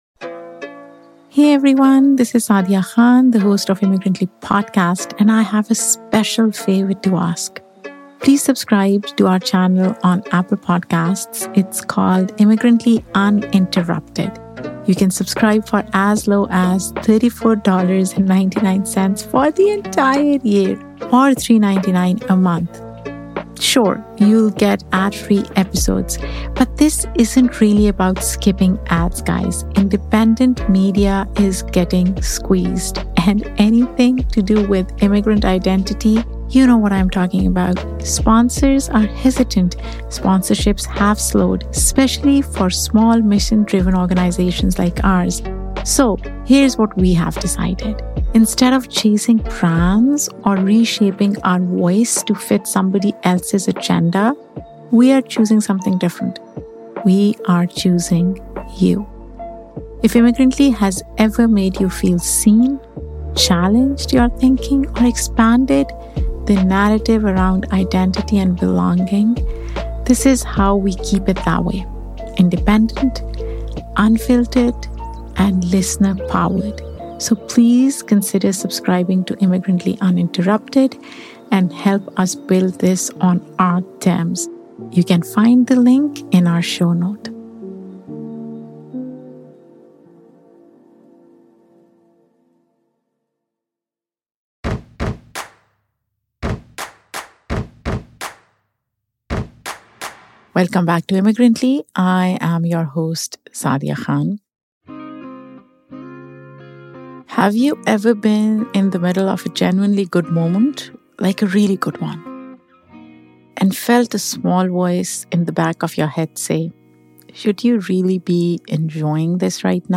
How do you let yourself celebrate Eid when the world feels like it's falling apart? In this solo episode